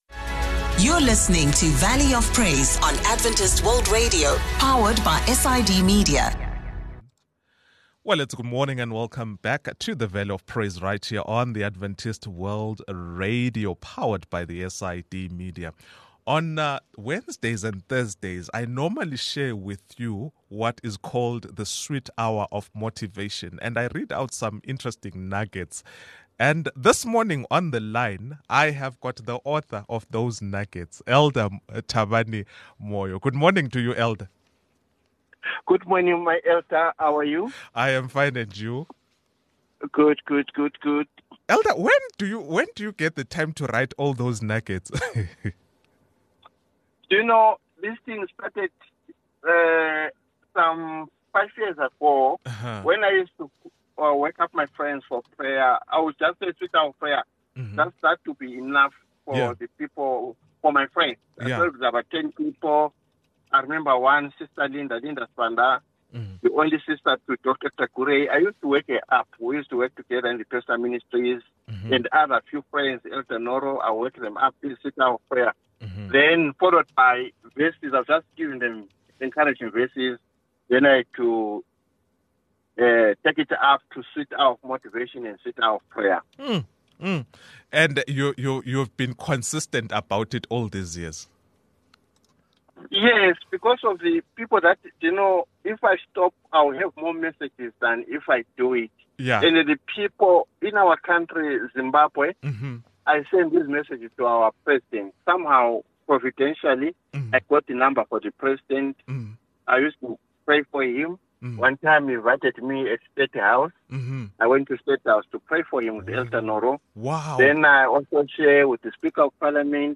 In this episode, we interview a family life counselor